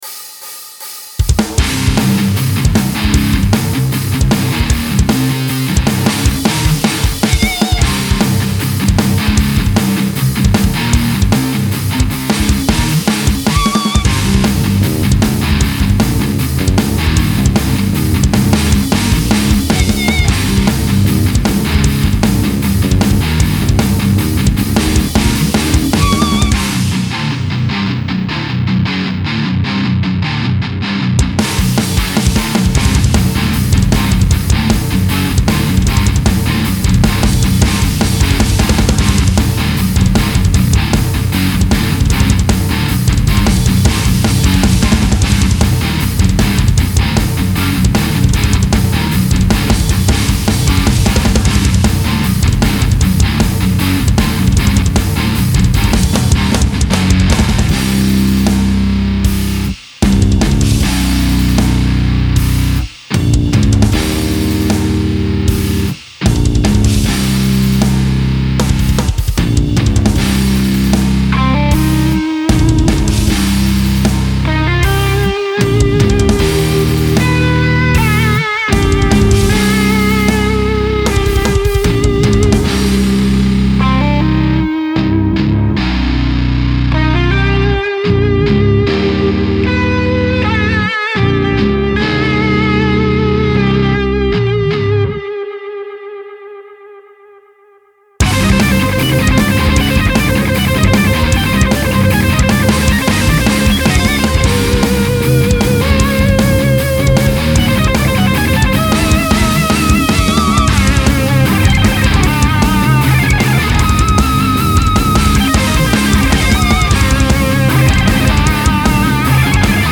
Style Style Metal
Mood Mood Aggressive, Dark, Intense
Featured Featured Bass, Drums, Electric Guitar
BPM BPM 154